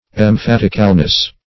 Search Result for " emphaticalness" : The Collaborative International Dictionary of English v.0.48: Emphaticalness \Em*phat"ic*al*ness\, n. The quality of being emphatic; emphasis.